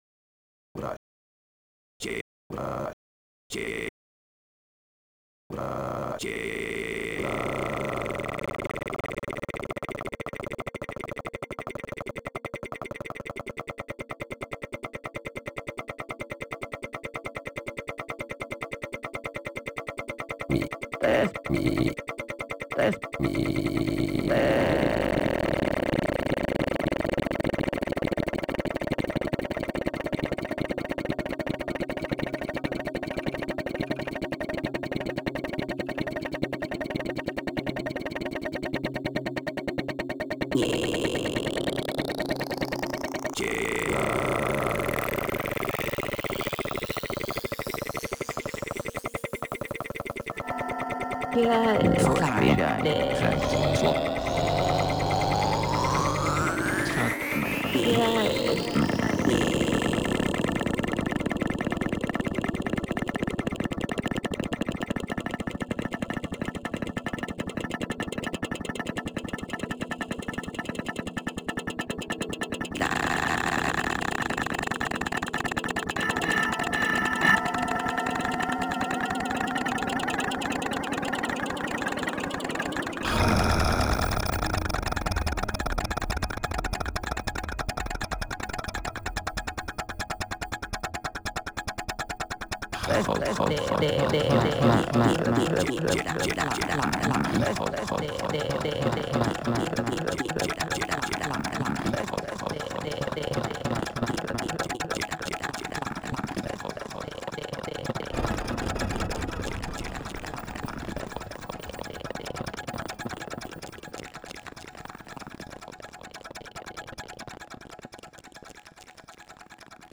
This is based on the voices of